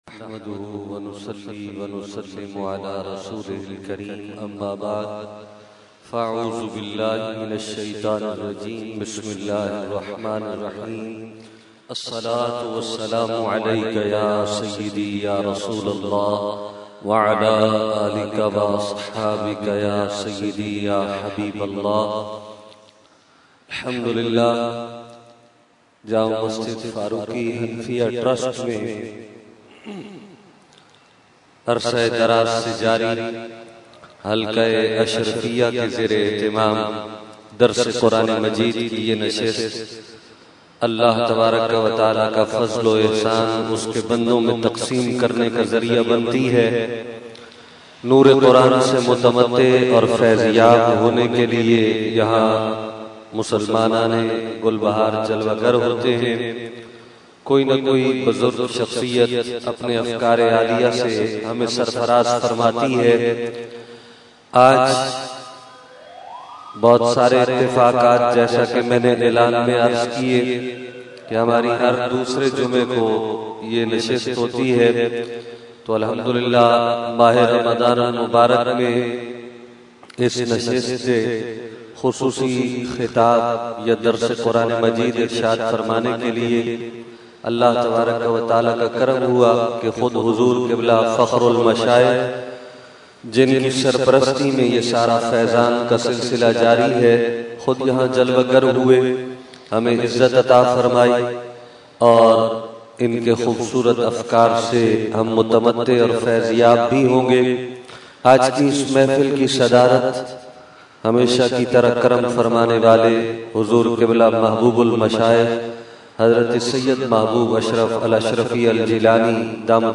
Category : Qirat | Language : ArabicEvent : Dars Quran Farooqi Masjid 10 August 2012